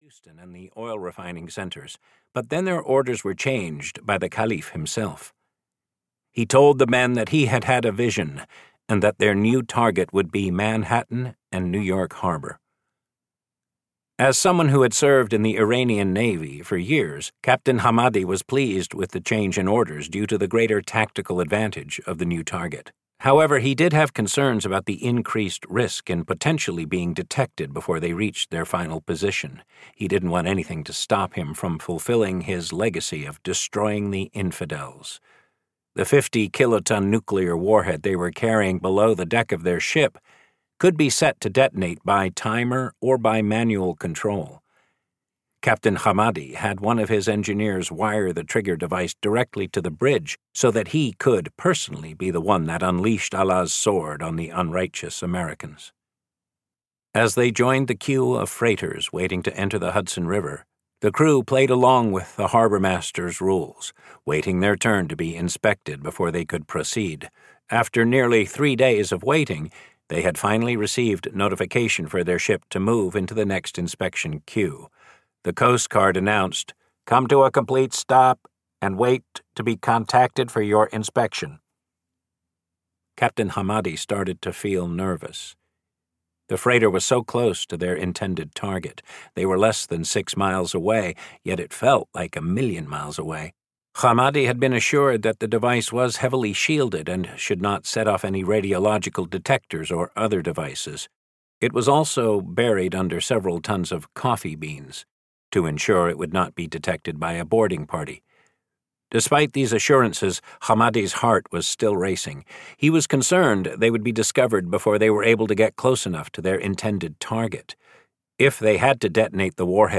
Audio knihaOperation Red Dragon and the Unthinkable (EN)
Ukázka z knihy